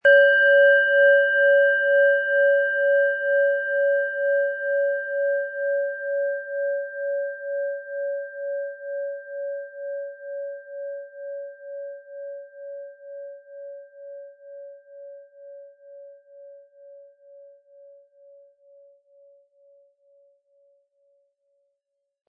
Planetenton 1
Diese tibetanische Pluto Planetenschale kommt aus einer kleinen und feinen Manufaktur in Indien.
Um den Originalton der Schale anzuhören, gehen Sie bitte zu unserer Klangaufnahme unter dem Produktbild.
MaterialBronze